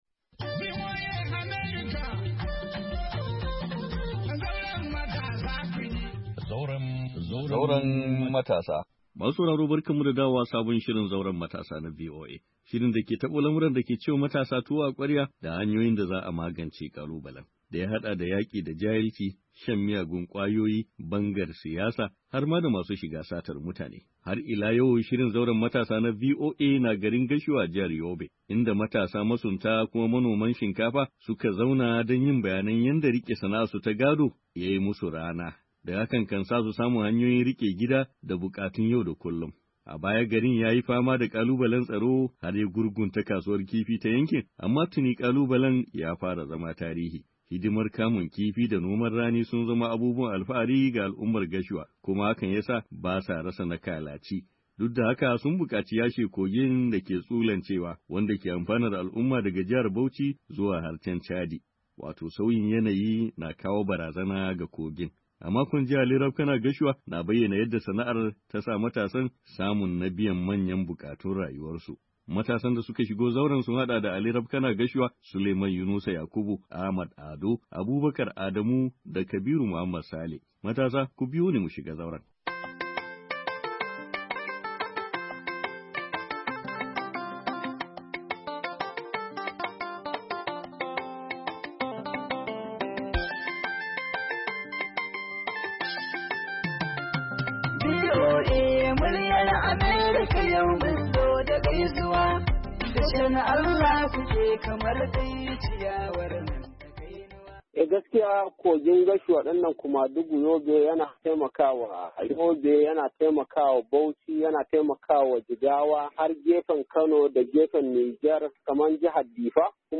Zauren Matasa na ci gaba da kawo muku zantawa da matasan garin Gashua a jihar Yabe mai albarkar kifi da kuma noman rani, kasancewar kogi da ya ratsa garin. Bakin masunta kan shiga Gashua don samun na su rabon tare da sauran 'yan gari.